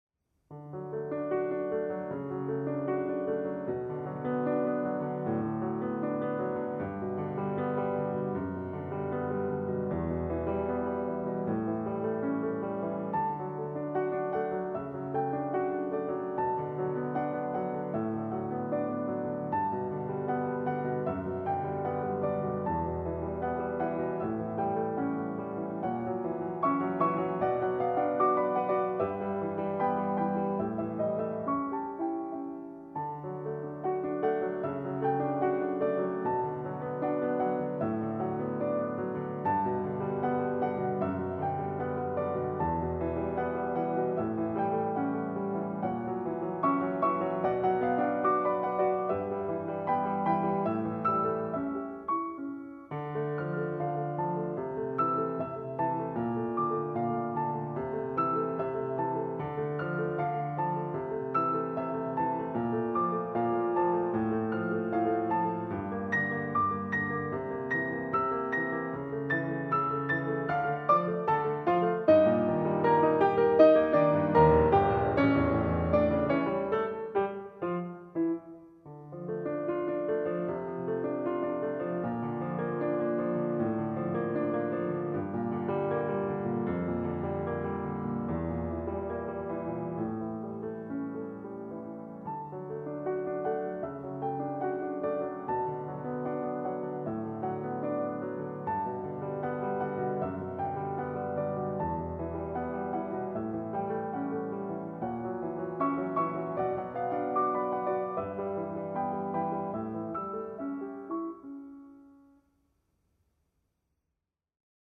Genre :  ChansonComptine
Style :  Avec accompagnement
Enregistrement piano seul